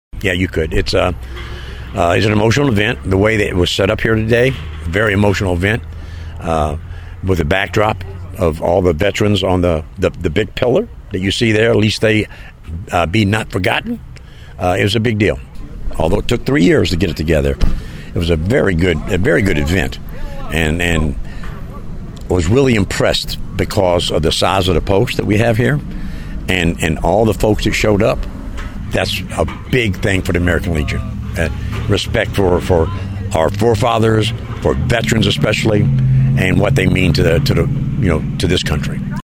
A large crowd gathered at the Hickman City Cemetery on Friday to honor a Civil War hero.